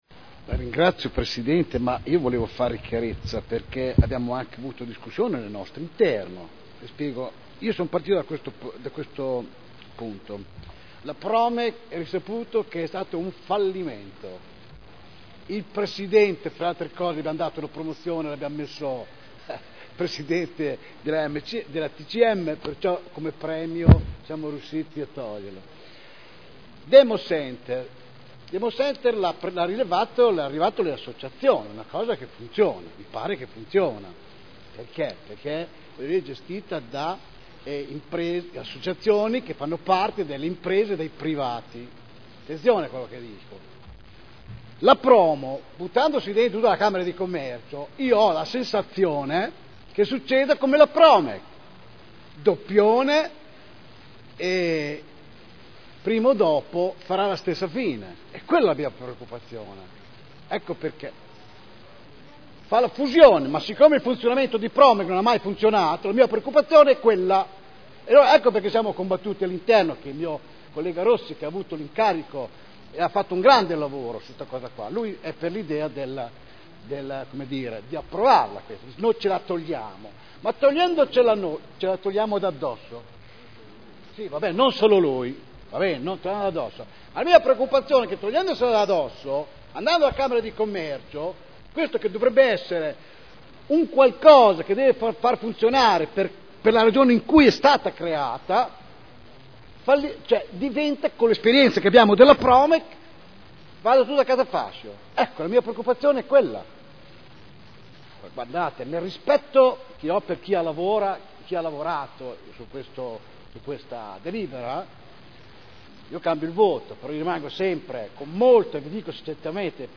Seduta del 10/12/2009. Ridefinizione della missione della compagine societaria di PROMO S.c.a.r.l. – Vendita alla C.I.A.A. di Modena di parte della quota societaria e di un’area limitrofa alla sede (Commissione consiliare del 26 novembre 2009) – Presentato emendamento prot. 151289 in data 30.11.2009 - Dichiarazione di voto